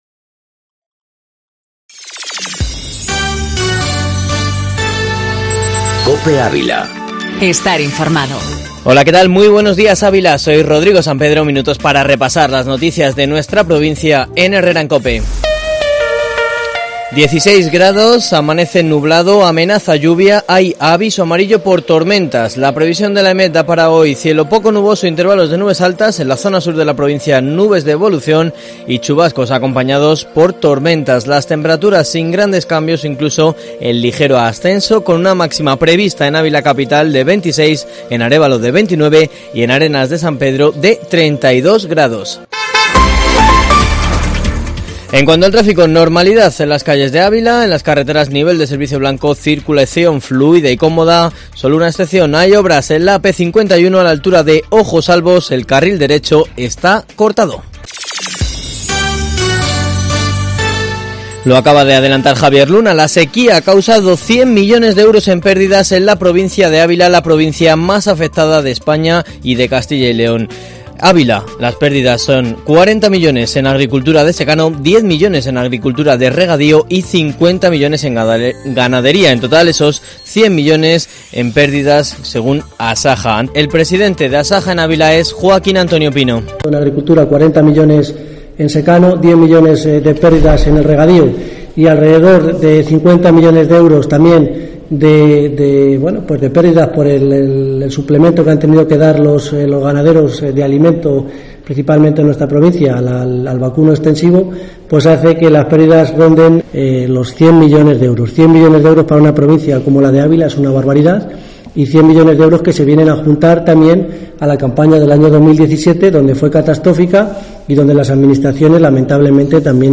Informativo matinal Herrera en COPE Ávila 21/08/2019